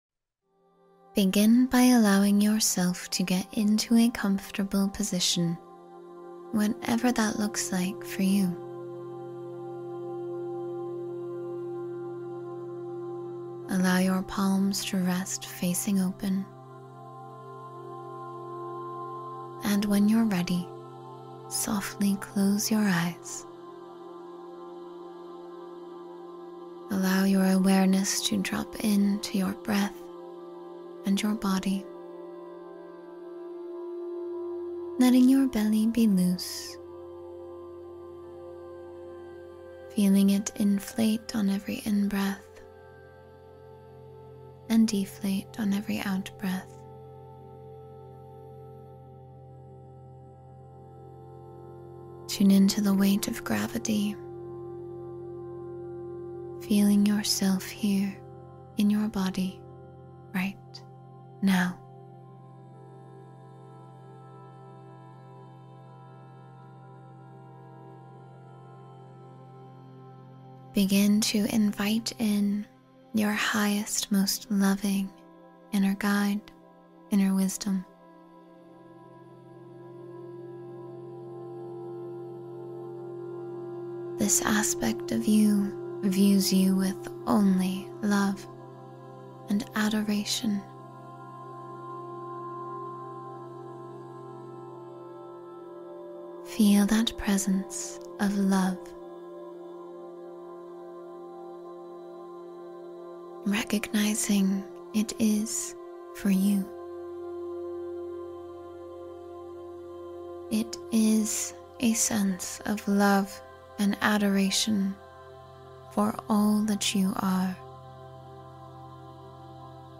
Every breath, every guided word, every moment of silence brings you closer to the version of yourself that feels grounded, open, and alive.